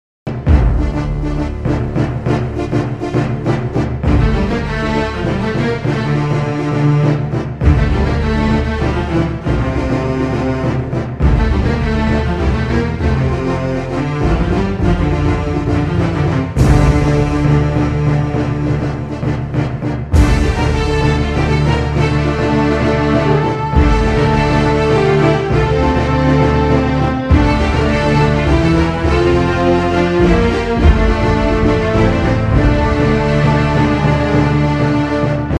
• Качество: 128, Stereo
саундтреки
без слов